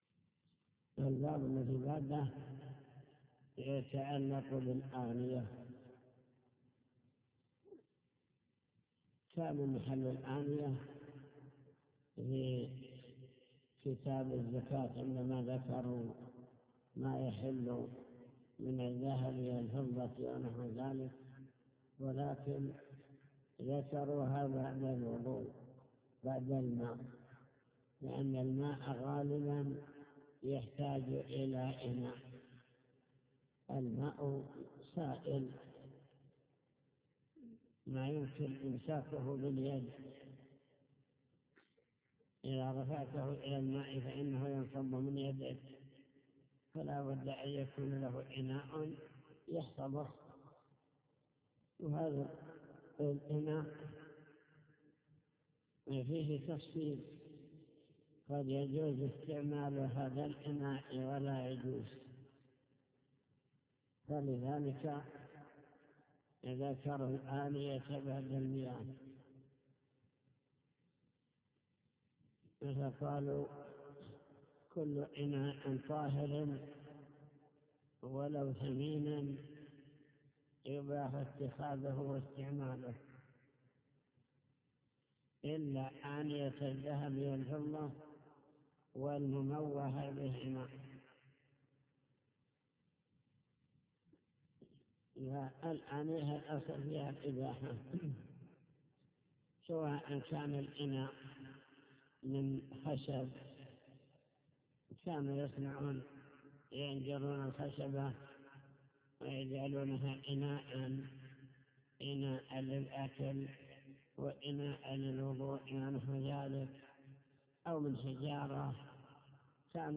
المكتبة الصوتية  تسجيلات - كتب  شرح كتاب دليل الطالب لنيل المطالب كتاب الطهارة باب الآنية